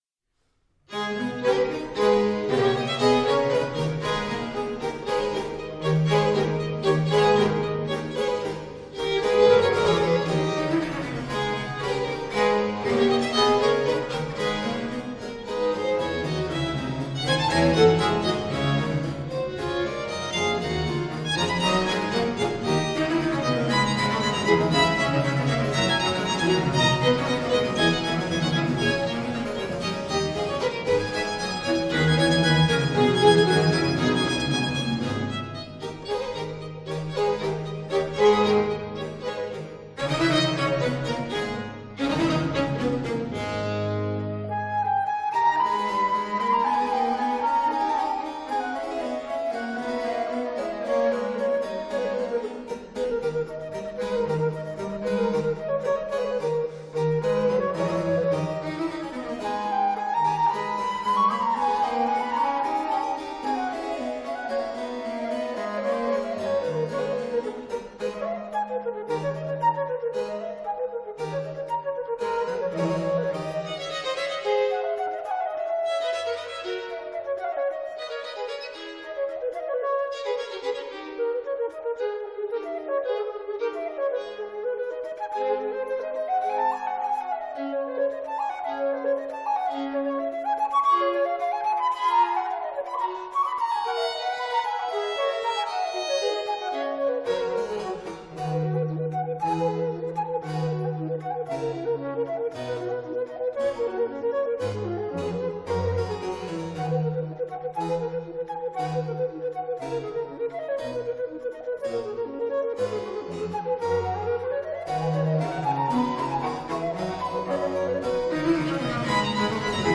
Satz Allegro (rechte Maustaste)